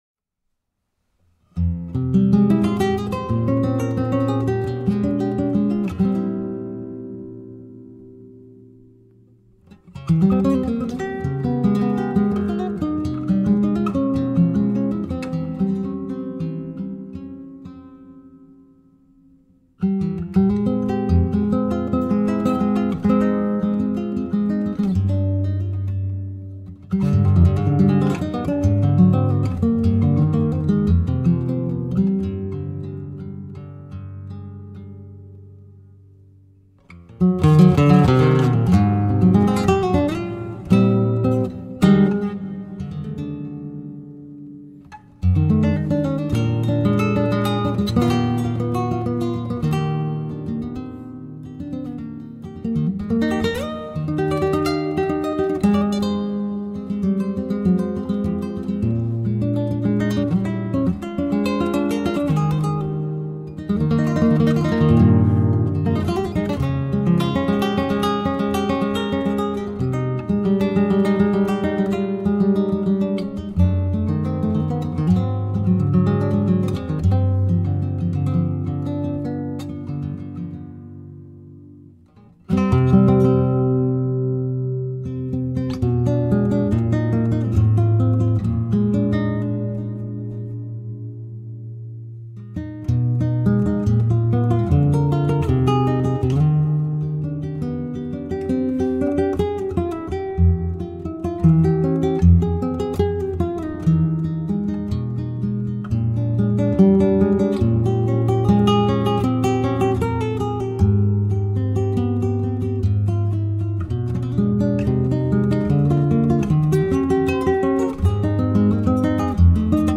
La Musique